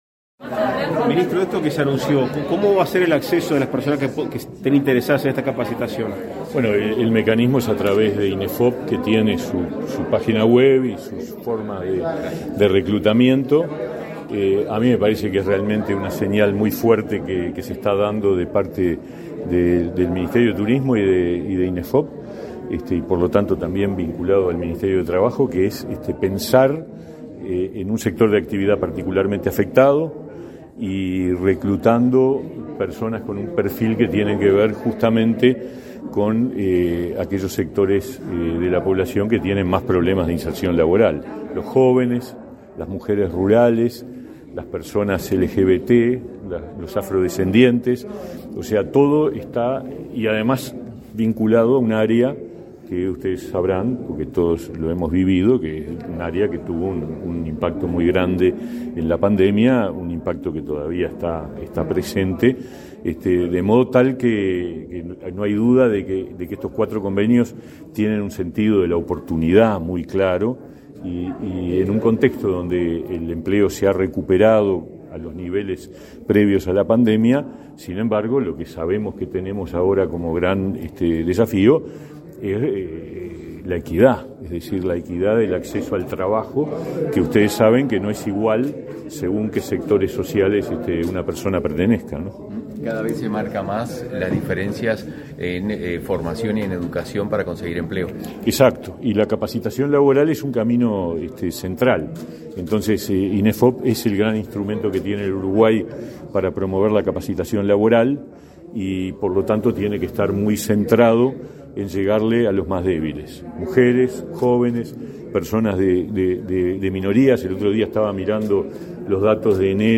Declaraciones a la prensa del ministro de Trabajo y Seguridad Social, Pablo Mieres
Este 16 de marzo, el Ministerio de Turismo y el de Trabajo y Seguridad Social, mediante el Instituto Nacional de Empleo y Formación Profesional (Inefop), firmaron convenios de capacitación para poblaciones socialmente vulnerables. Tras el evento, realizado este 16 de marzo, el ministro Pablo Mieres efectuó declaraciones a la prensa.